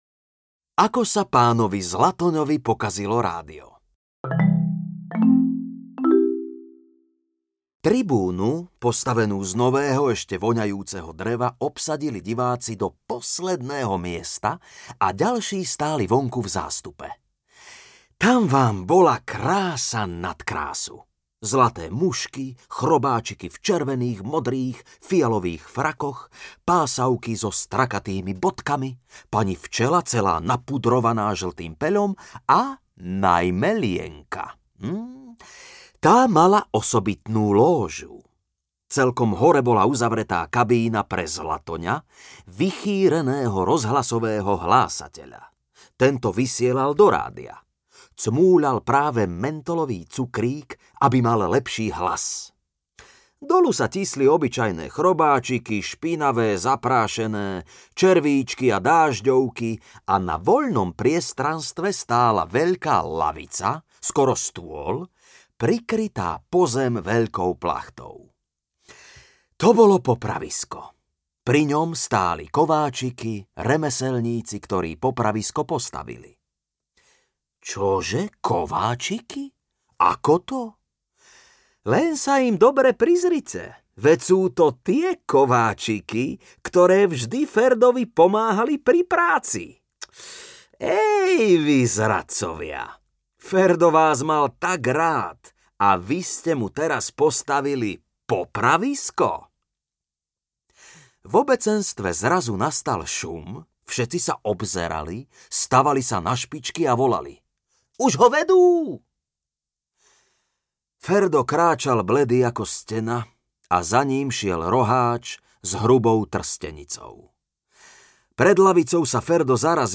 Knižka o Ferdovi Mravcovi audiokniha
Ukázka z knihy
• InterpretJuraj Kemka, František Kovár, Juraj Loj